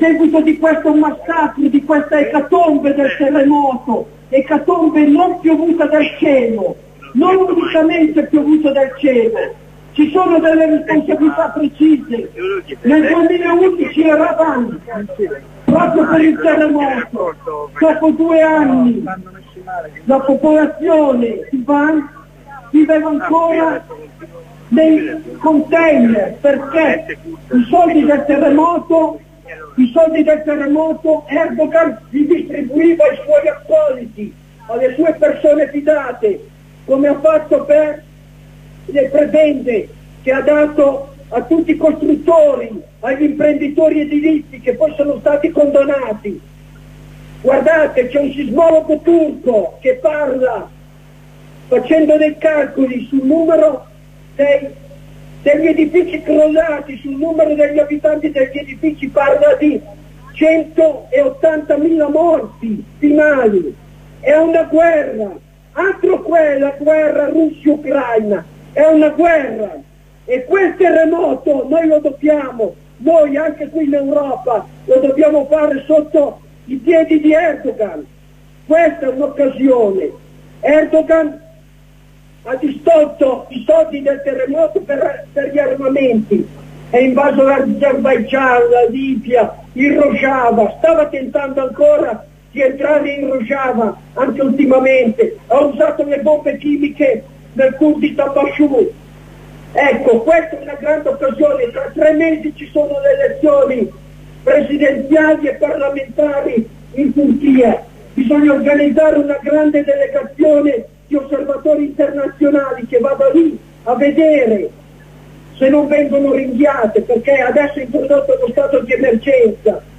Libertà per Ocalan - interventi dal corteo
Serie di corrispondenze e contributi dalla manifestazione nazionale di Roma per la liberazione di Ocalan
corteo ocalan 1.ogg